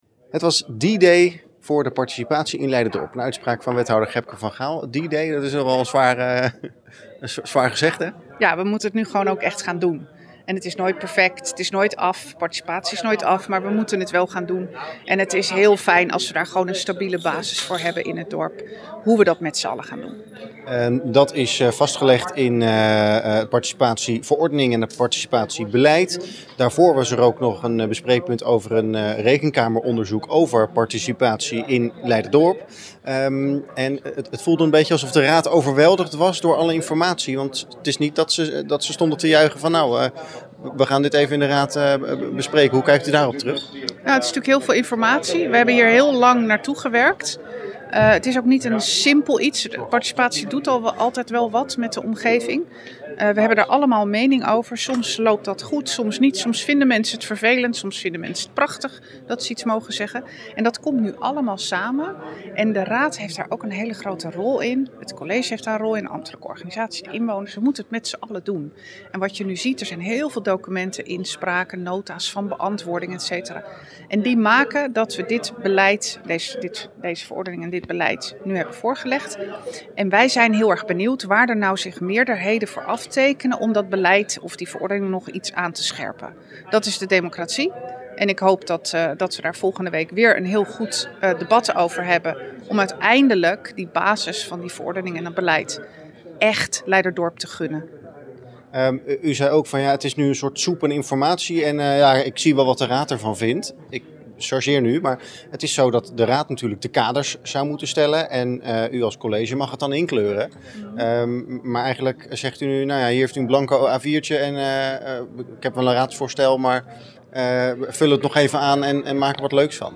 Wethouder Gebke van Gaal over de participatieplannen.
wethouder-van-gaal-over-participatieplannen.mp3